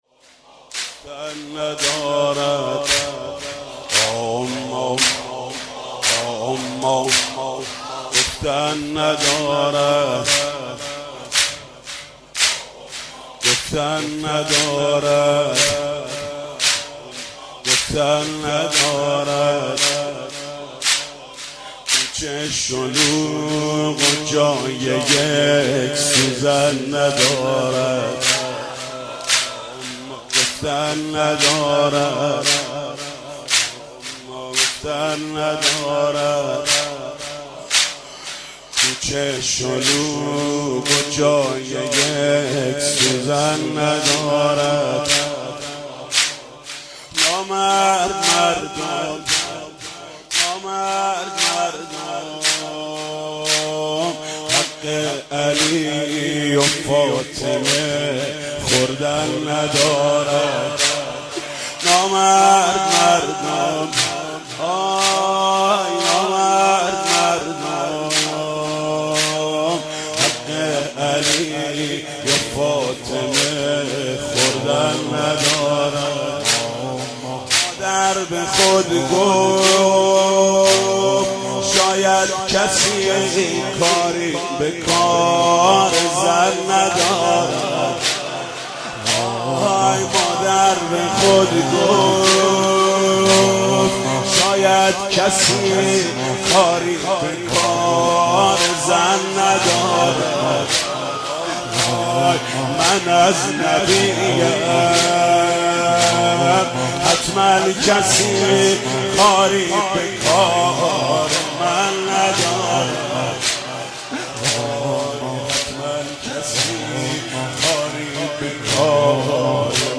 «فاطمیه 1392» زمینه: گفتن ندارد، کوچه شلوغ و جای یک سوزن ندارد